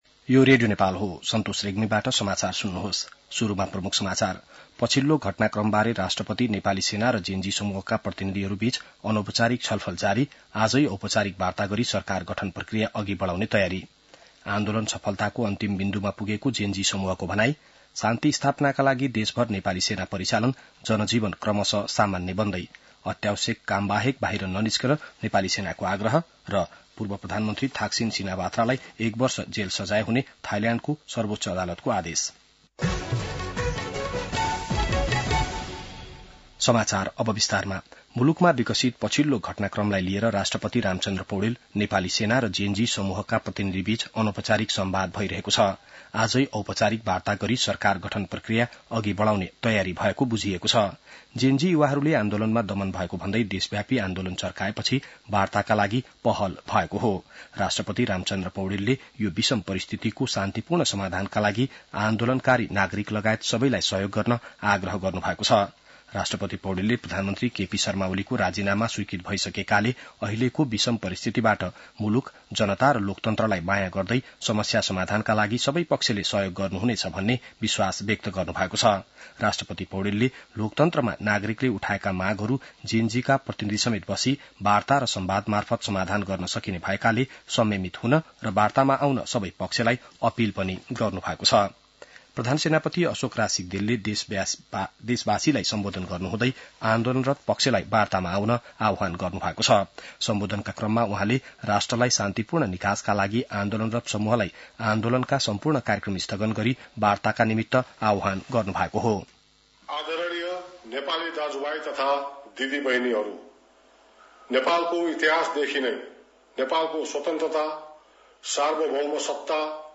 बिहान ९ बजेको नेपाली समाचार : २५ भदौ , २०८२